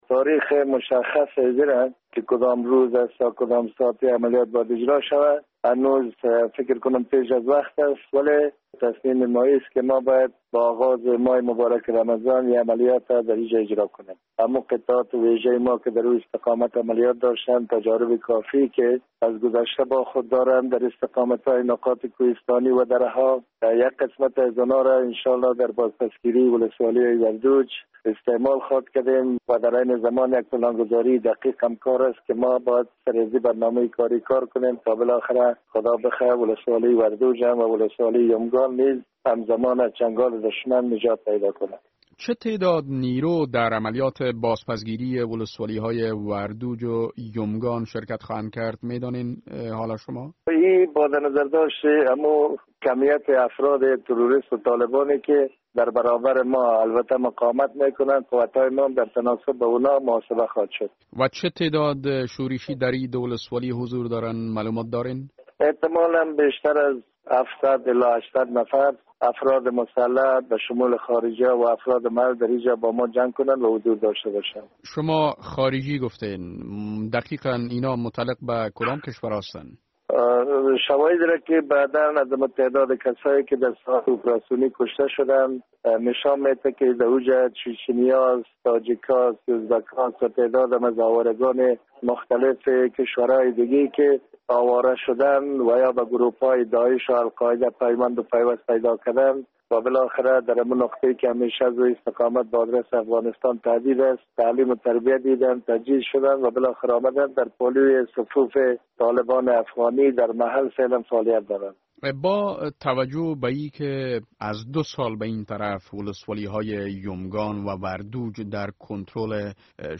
مصاحبه - صدا
این را افضل امان، معیین وزارت دفاع ملی افغانستان امروز (سه شنبه) از ولسوالی بارک بدخشان به رادیو آزادی گفت.